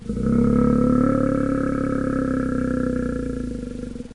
wwildlife_bear.ogg